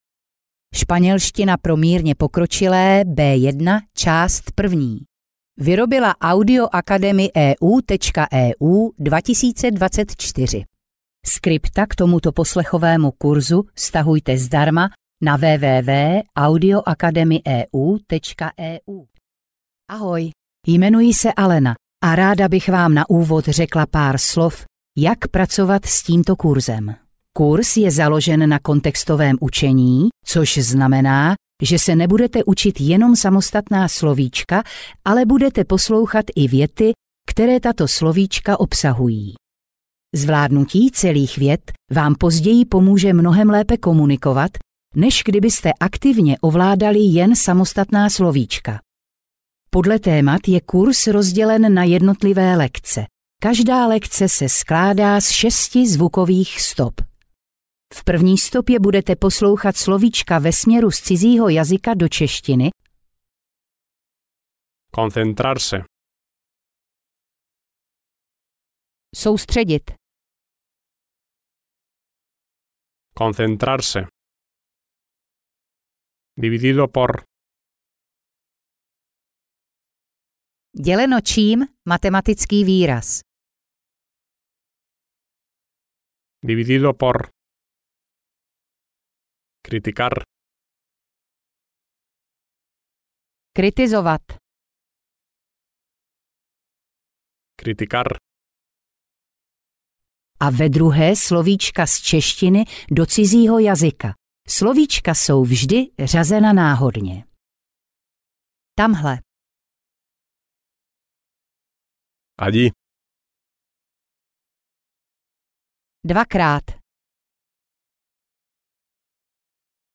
Španělština pro mírně pokročilé B1 – část 1 audiokniha
Ukázka z knihy